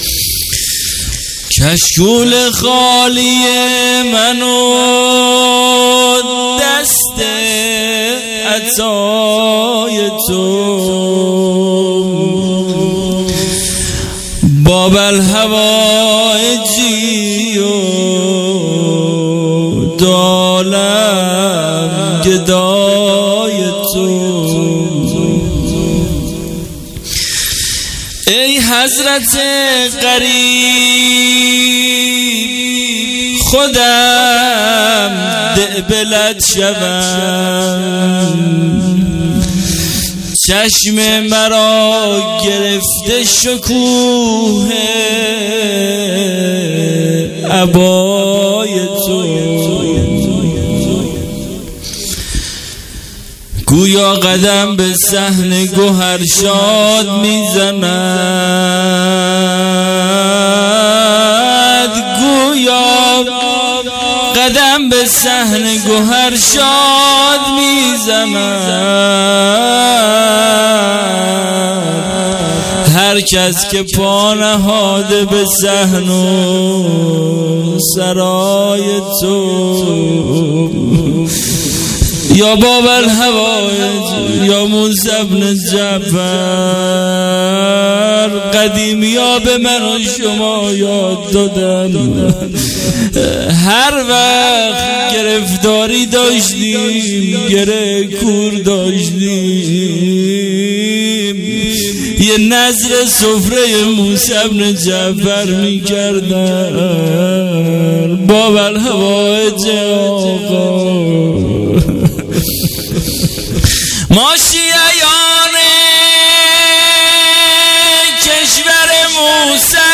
روضه
قم عزای شهادت امام کاظم (ع) اسفند 99 اشتراک برای ارسال نظر وارد شوید و یا ثبت نام کنید .